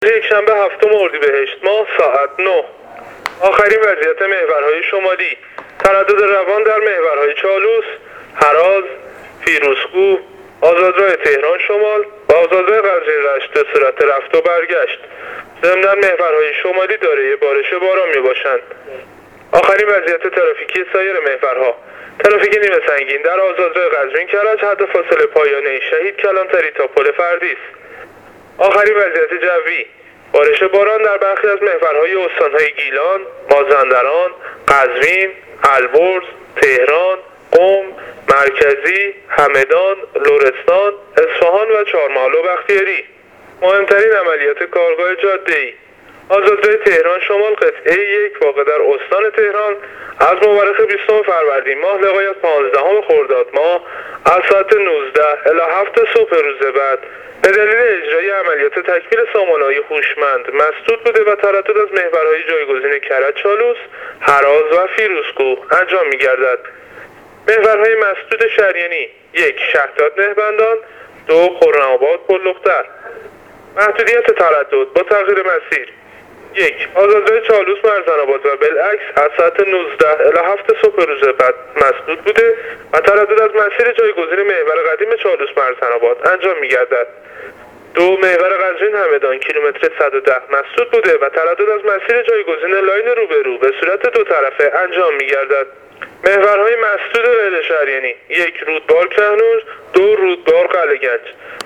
گزارش رادیو اینترنتی از آخرین وضعیت ترافیکی جاده‌ها تا ساعت ۹ هفتم اردیبشهت ۱۳۹۹